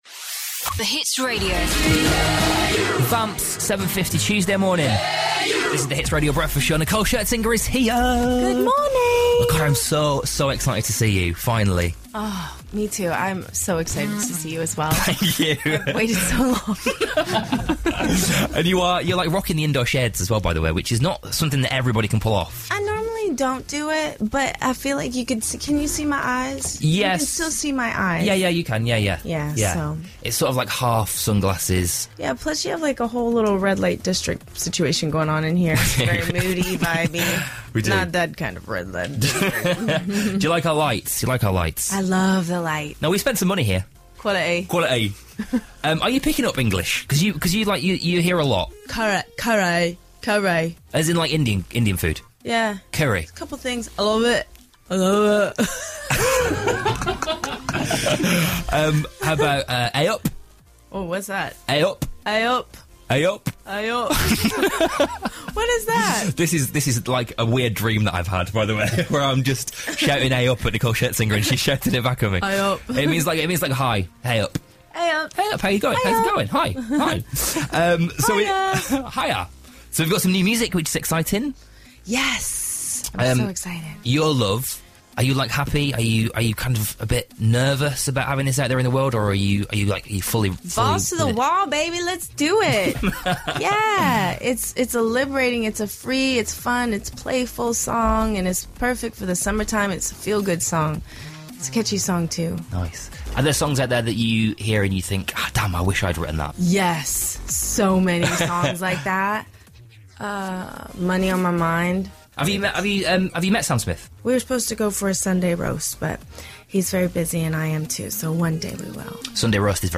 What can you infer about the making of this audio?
in the studio